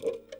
New floppy sound samples
35_spin_start_loaded.wav